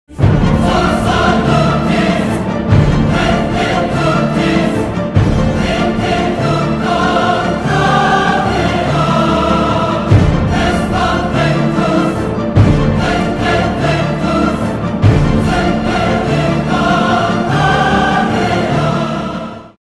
Зловещая мелодия